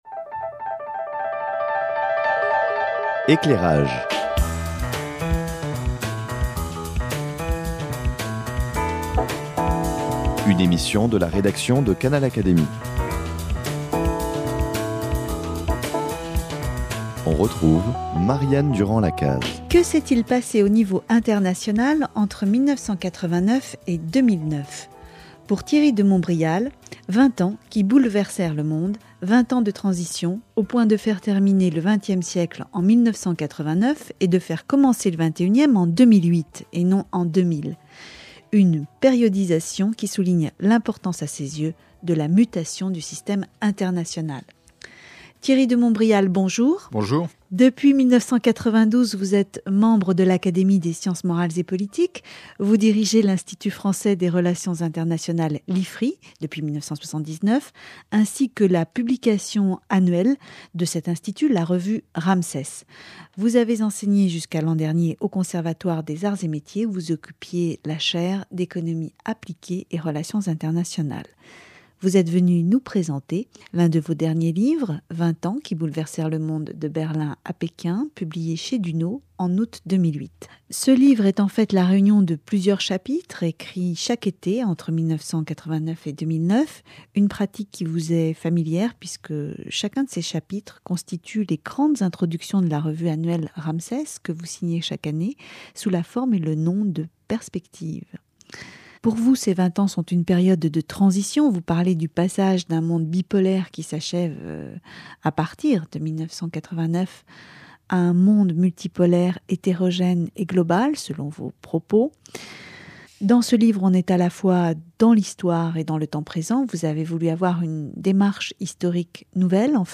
Au cours de cet entretien, il fait part de ses remarques sur la récession actuelle, sur le G20 qui vient de se tenir à Londres en avril 2009, une opération d'abord psychologique au sens d'un retour à la confiance, sur le retour de la politique, sur ses leaders actuels, sur la montée en puissance de la Chine.